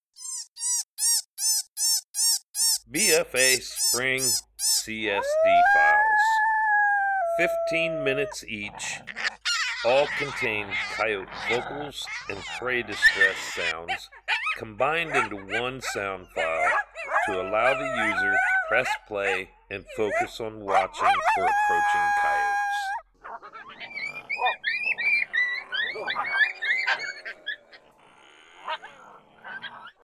Each BFA Spring CSD File is made up of our most popular Coyote Howls, Coyote Social Vocalizations, Coyote fights and Prey Distress Files.